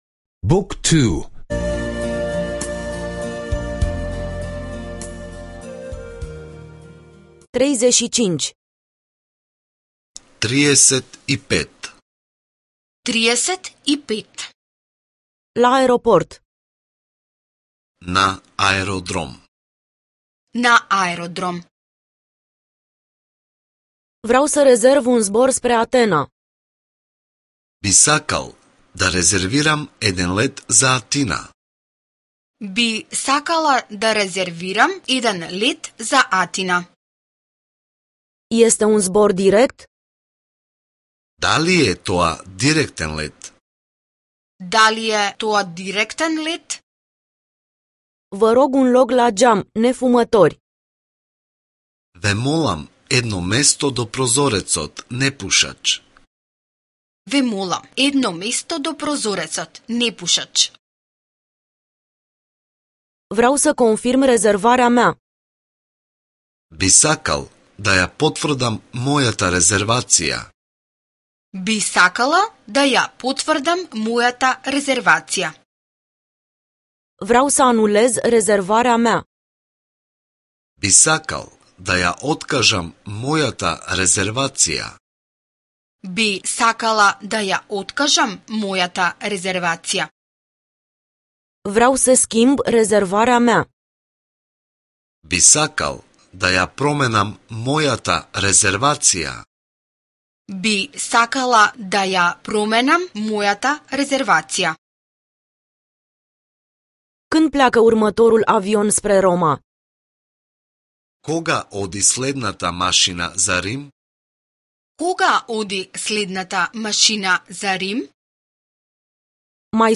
Lecții audio de limba macedonenă